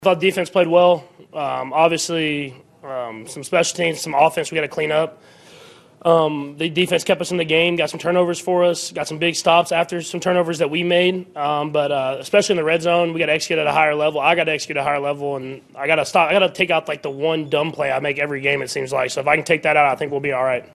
Chiefs Quarterback Patrick Mahomes says he needs to be better.
11-28-patrick-mahomes.mp3